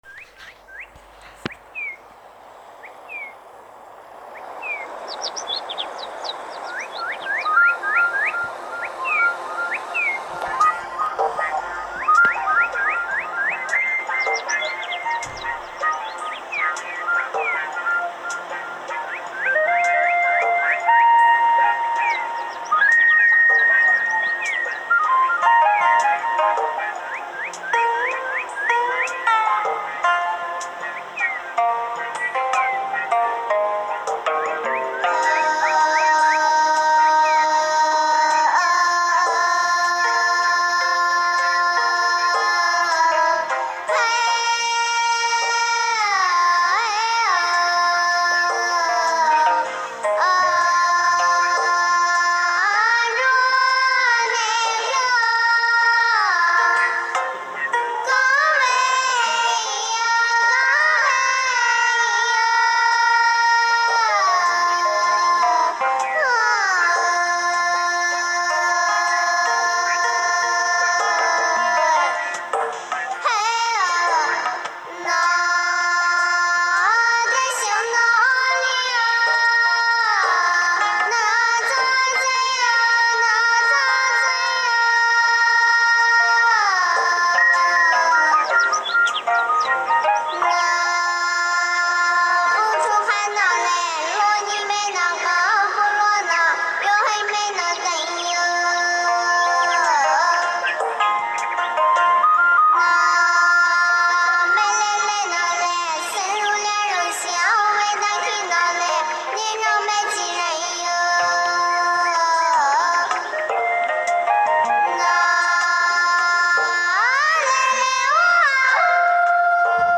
配乐苗歌.mp3